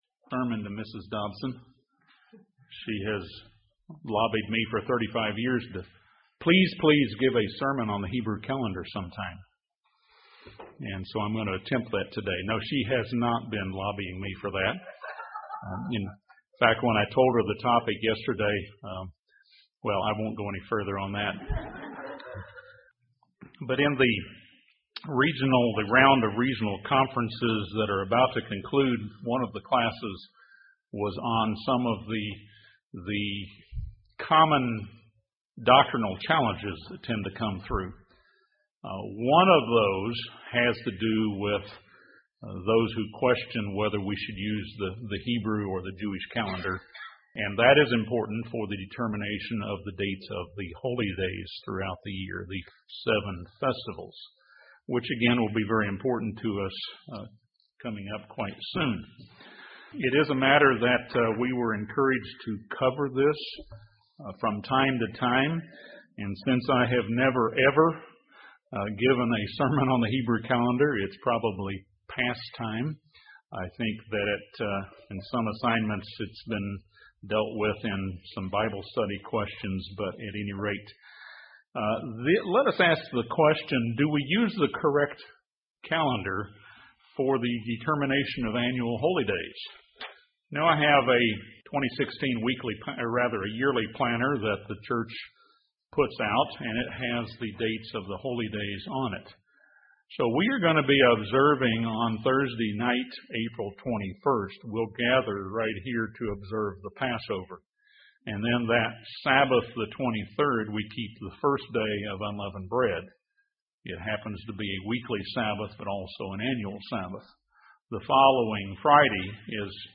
This sermon discusses the complexities of reconciling the monthly lunar cycle with the annual solar year. The Hebrew calendar is remarkably precise in how this is accomplished.